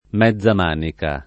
mezza manica [ m $zz a m # nika ]